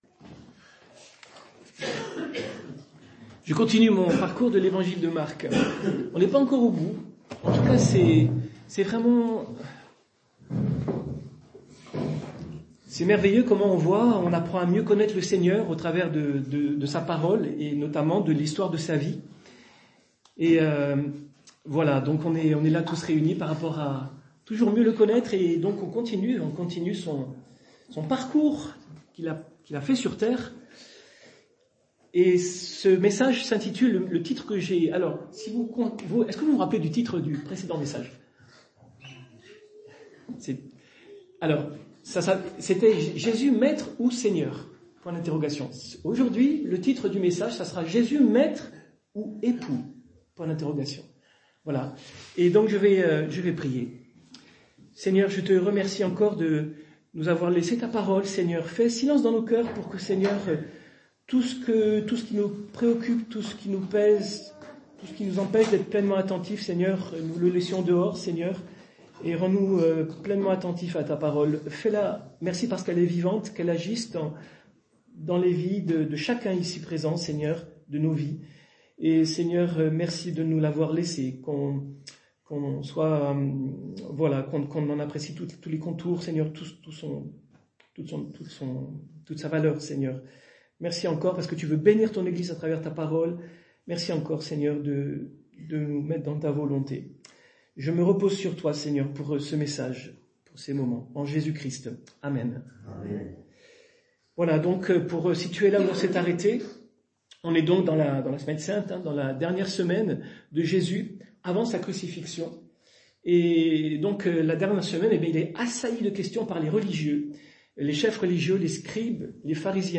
Culte du dimanche 3 novembre 2024 - EPEF